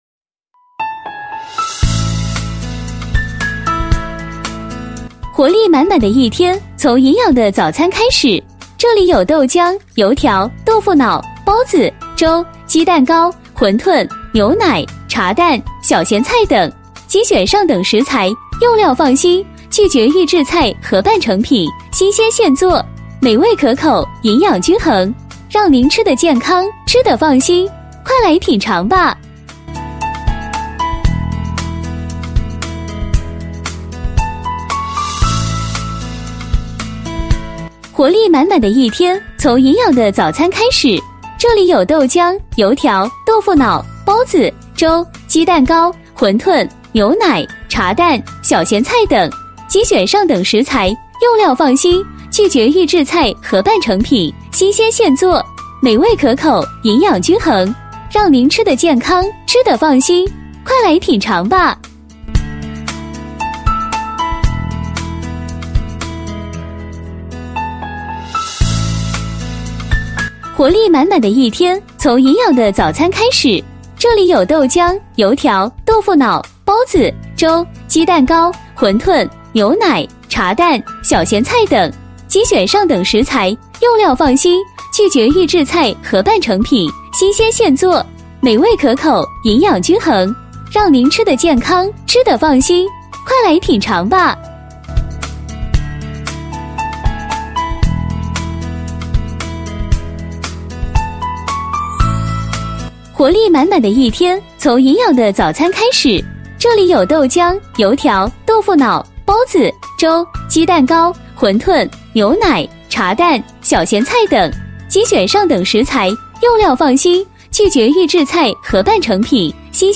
响亮有力的广告音制作